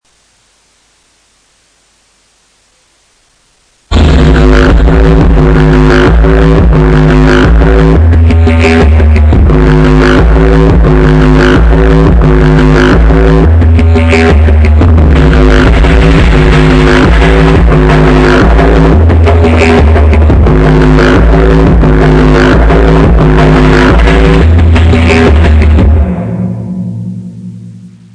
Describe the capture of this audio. I'm sorry about the poor quality, it has a too hard signal (don't turn the volume all the way up as it is distorted) I warned you don't play this at maximum volume because the signal is too hard